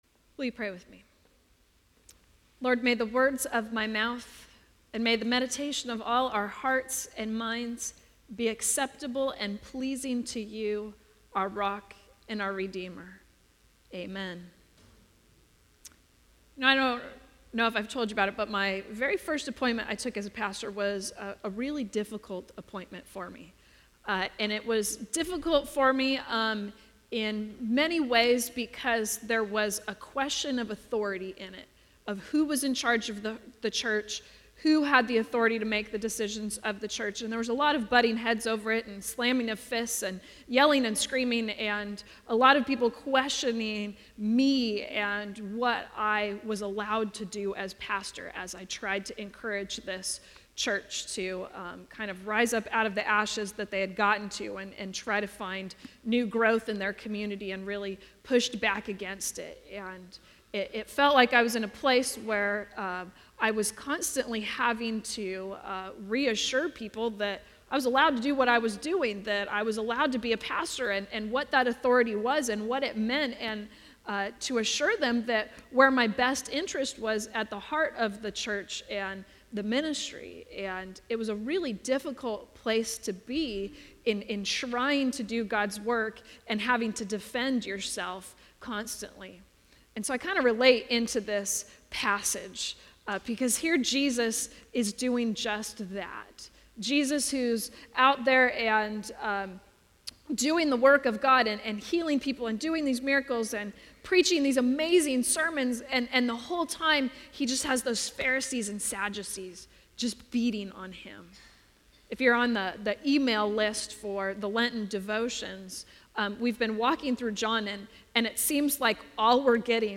9:30 AM Worship